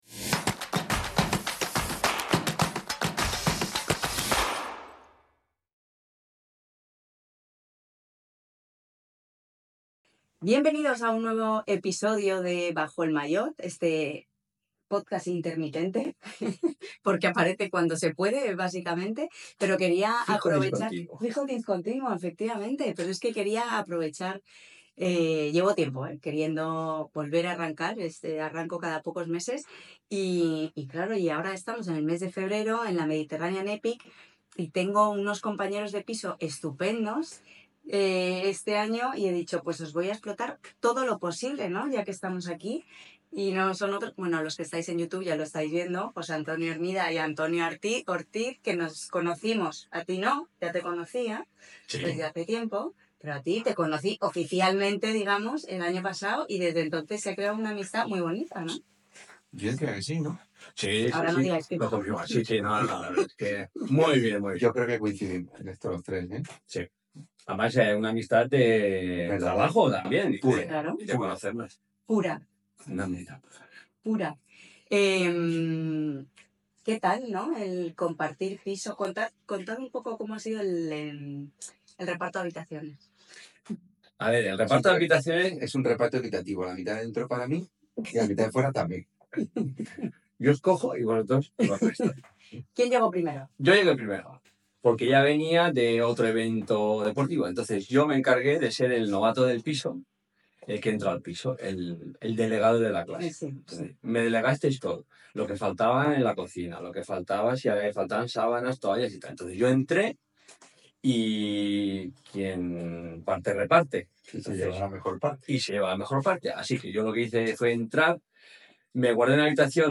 Una conversación divertida, como no...